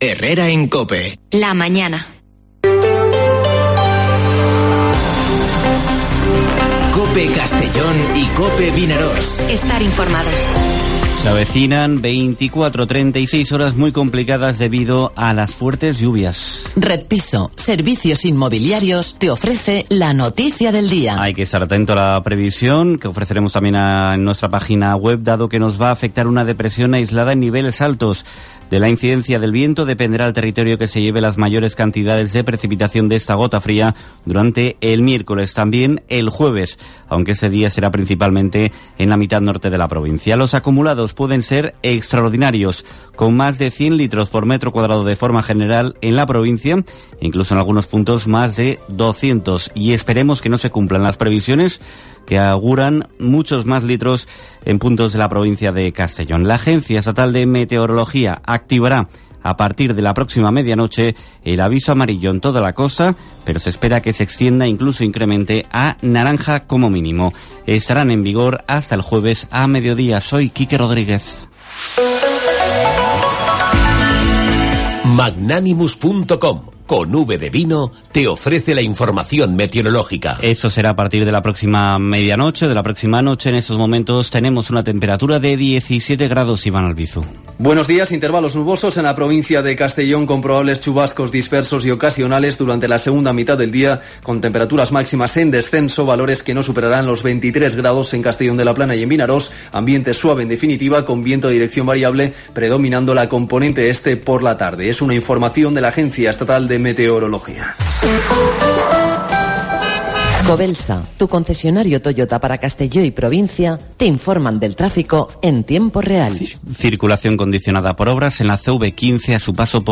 Informativo Herrera en COPE en la provincia de Castellón (21/09/2021)